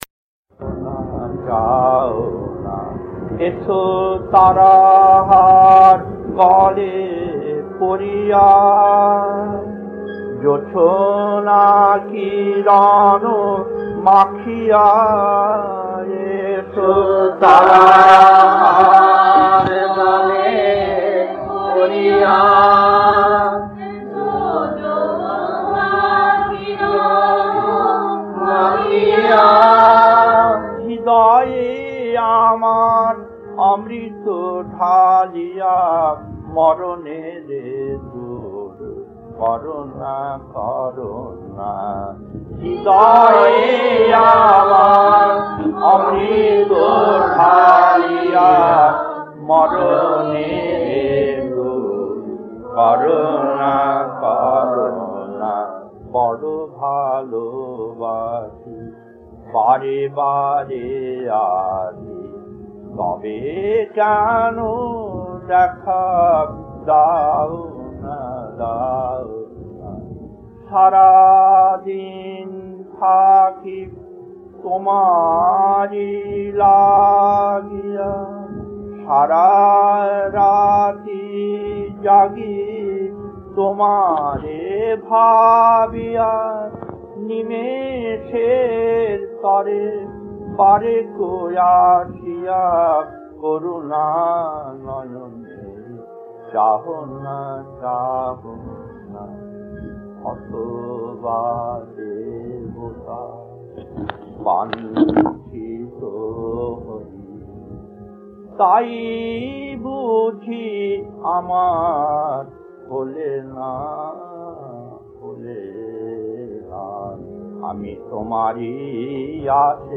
Kirtan A4-2 Puri December 1972 1.